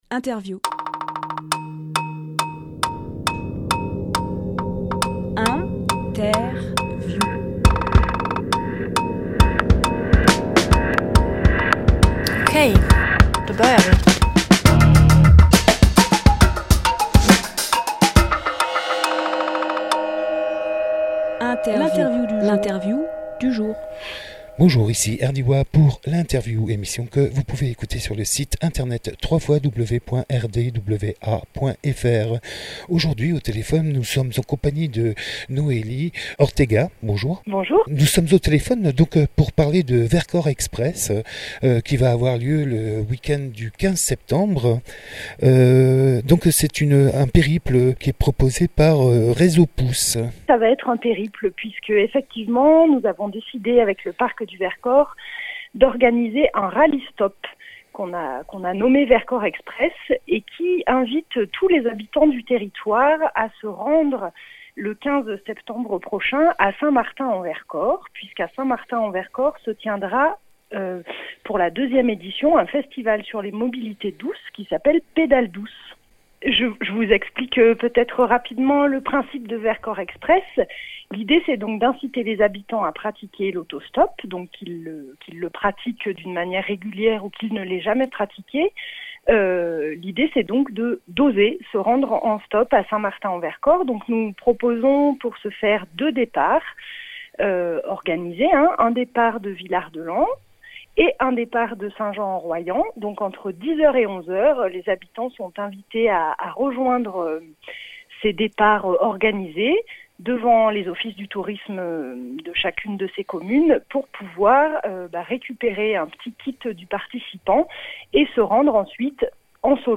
Emission - Interview Vercors Express avec RézoPouce Publié le 12 septembre 2018 Partager sur…
Lieu : Studio RDWA